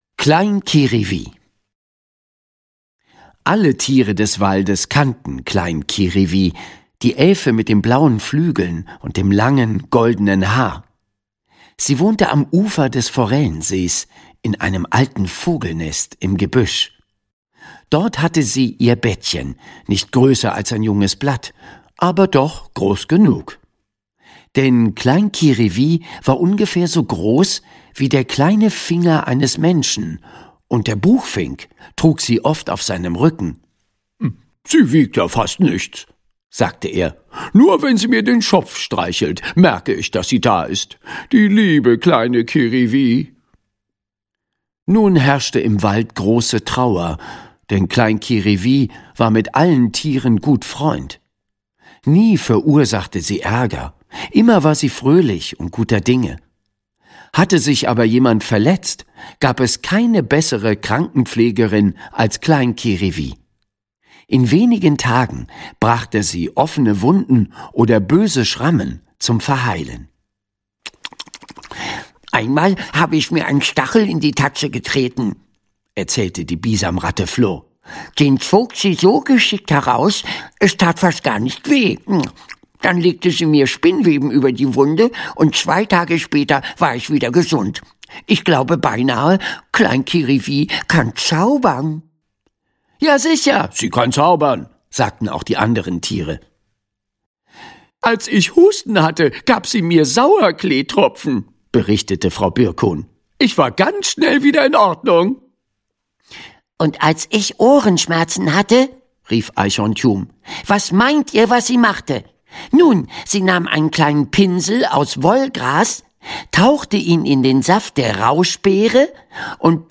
Latte Igel 2: Latte Igel reist zu den Lofoten - Sebastian Lybeck - Hörbuch